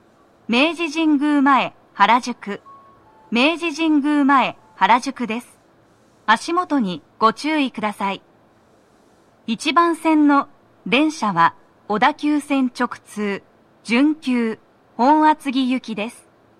足元注意喚起放送と、乗り換え放送が付帯するため、フルの難易度は高いです
女声
到着放送1